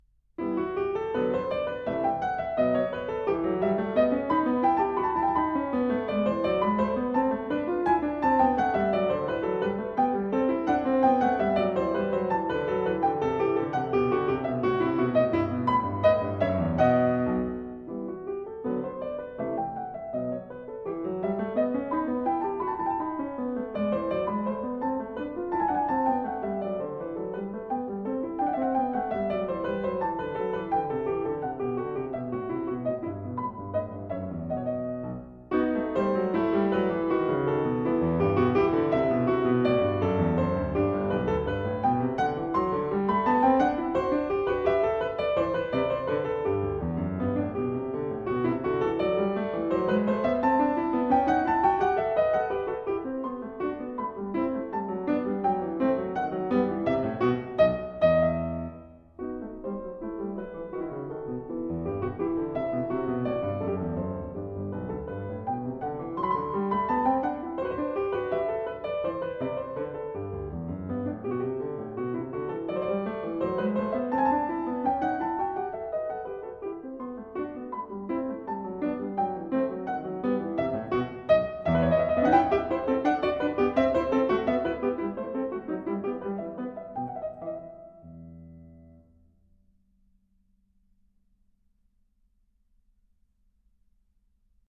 e-moll